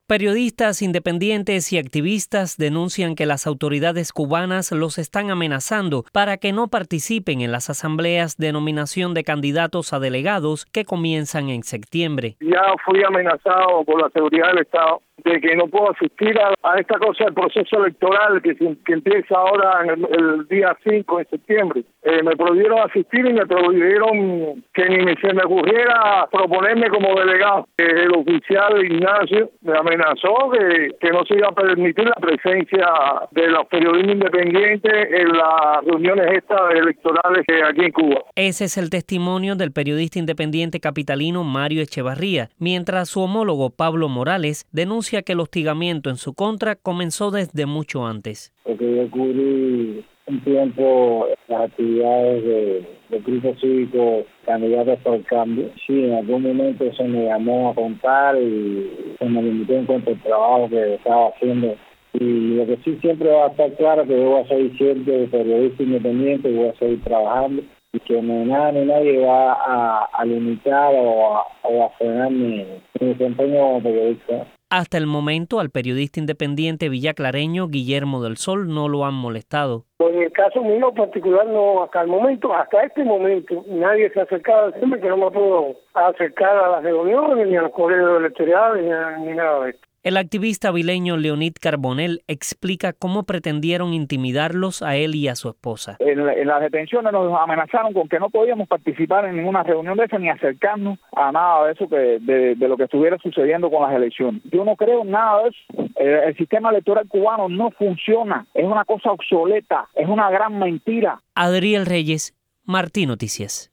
Declaraciones de periodistas independientes y opositores cubanos